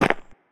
step-3.wav